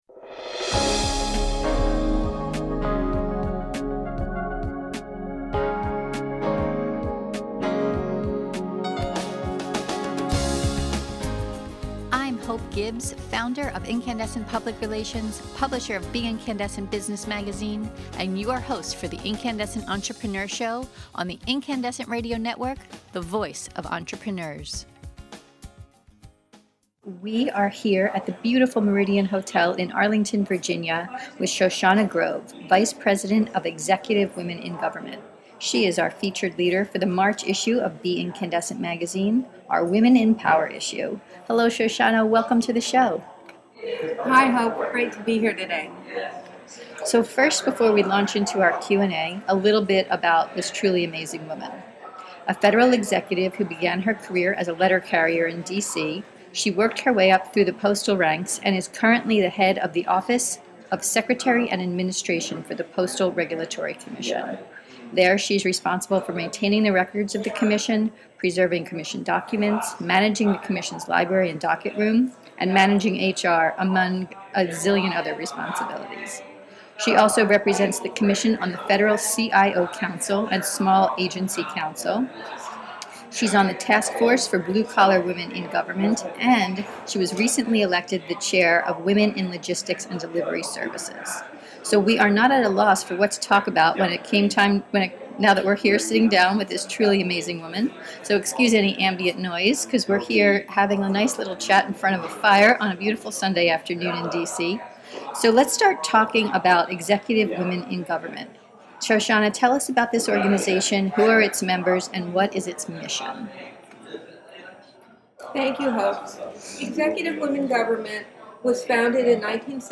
In this podcast interview we discuss: Executive Women in Government: It’s mission, members, and long-term goals.